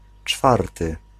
Ääntäminen
US : IPA : [fɔɹθ]